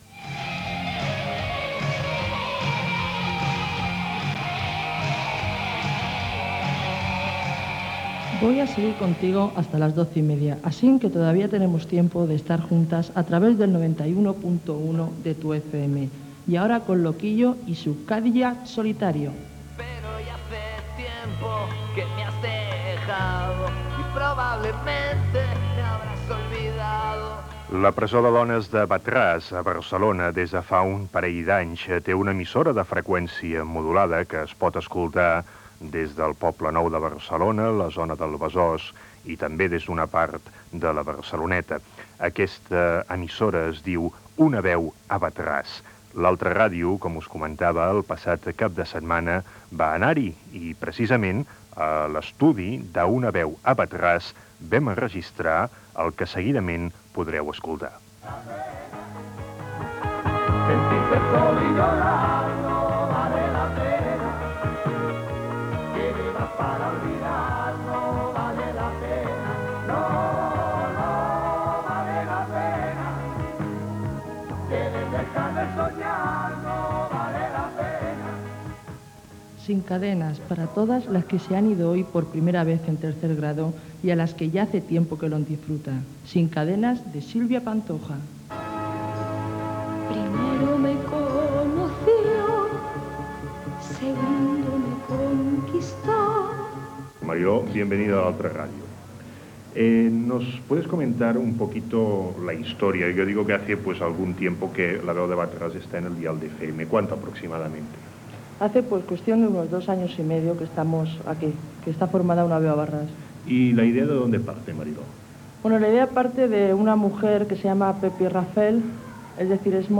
Fragment del programa fet a l'estudi de ràdio d'"Una veu a Wad-Ras", del centre penitenciari de dones de Wad-Ras de Barcelona Gènere radiofònic Divulgació